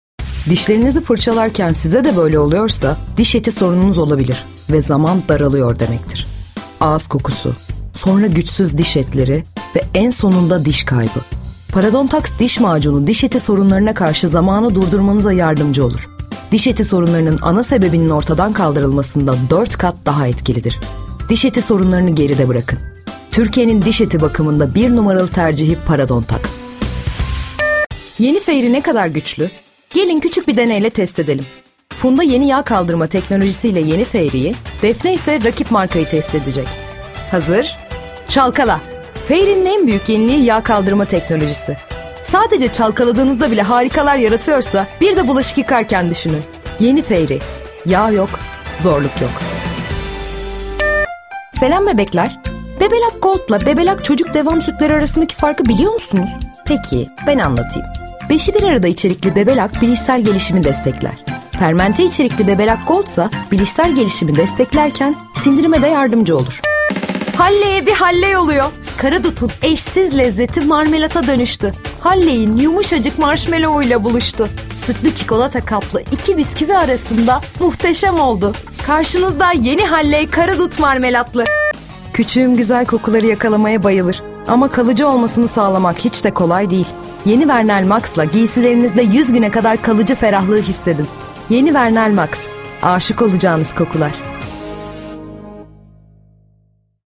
Advertising Film Voice Over
Commercial film voice-over | 6